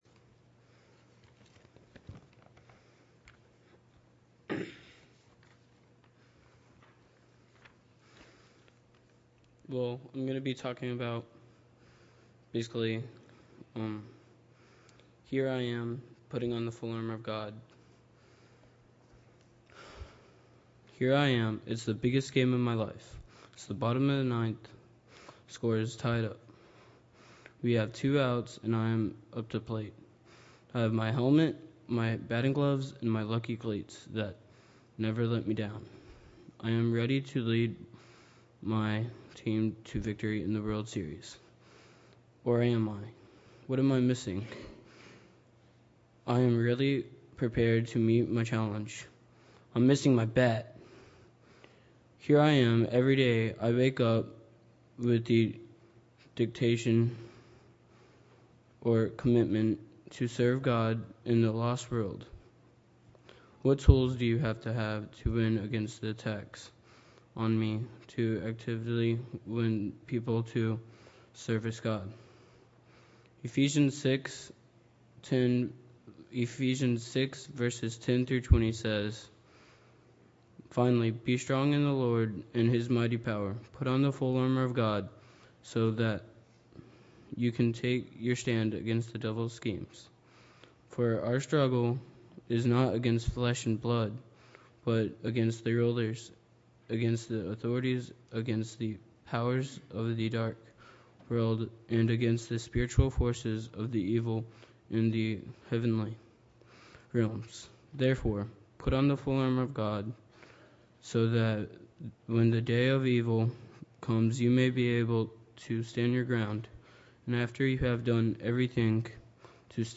Sunday PM Sermon